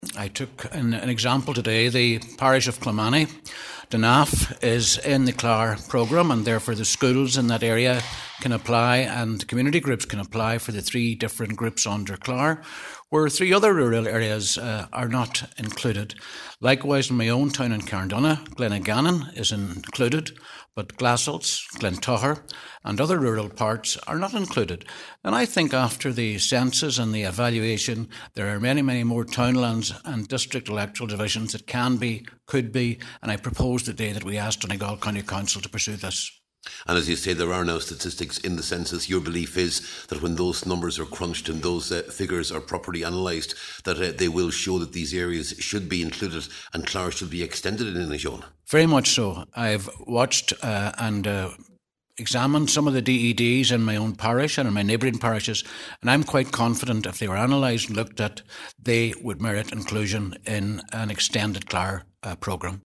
At a recent meeting of the Inishowen Municipal District, Cllr Albert Doherty said recent population trends require a re-evaluation of the eligible areas.